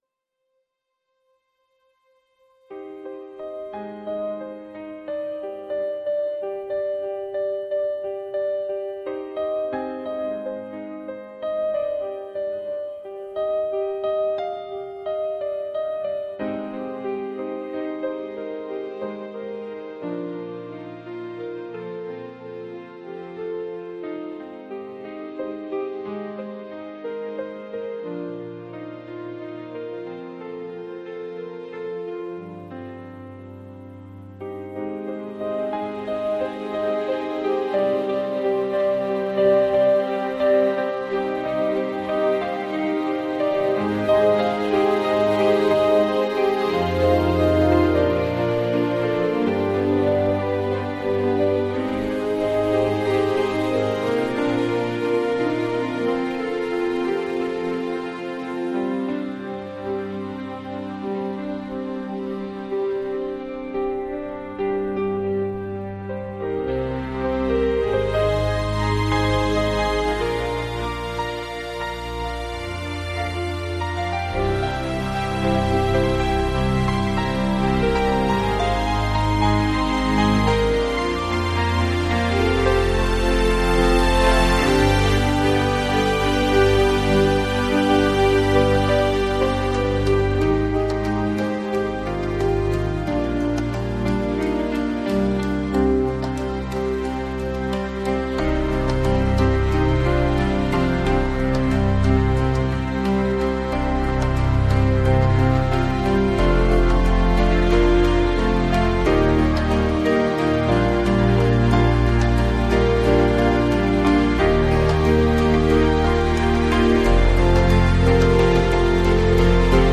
・アウトロはピアノが優しく旋律を奏でながら終わりを迎え、静かに曲が閉じられます。